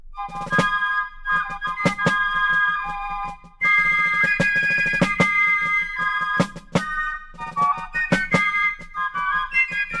Rossignol.ogg